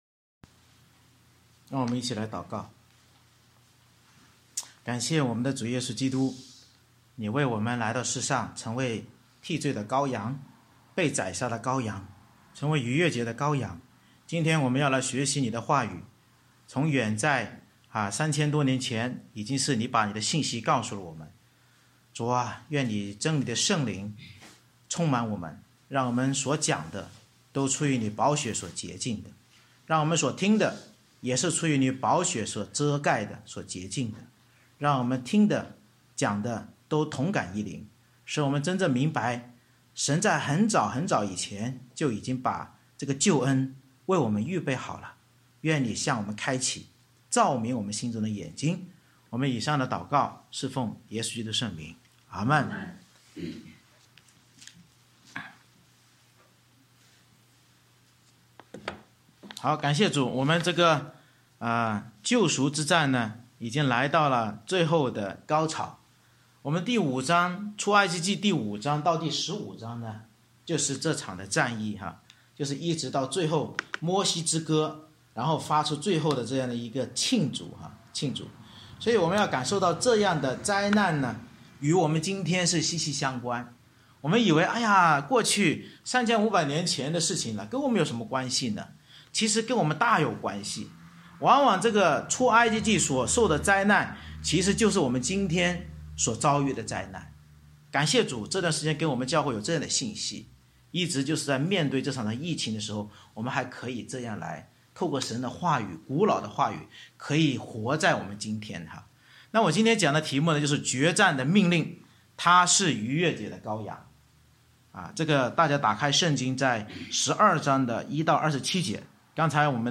《出埃及记》讲道系列 Passage